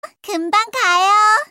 알림음 8_금방가요.mp3